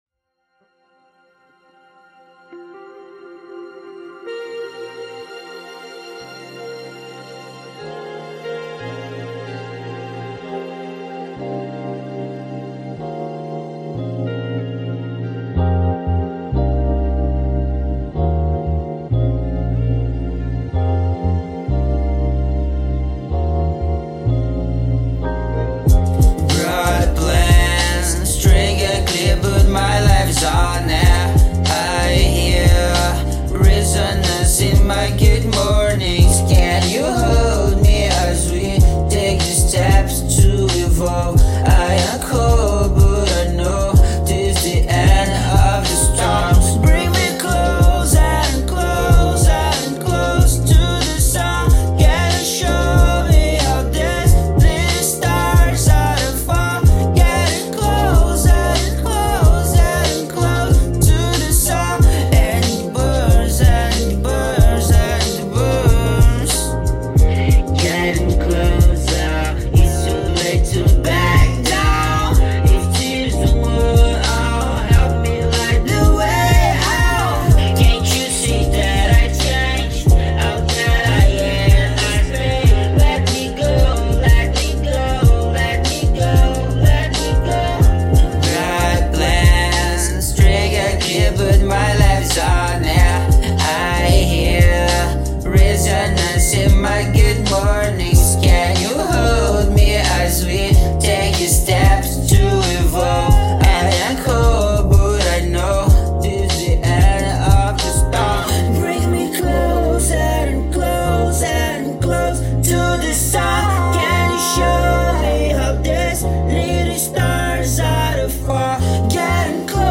EstiloEletrônica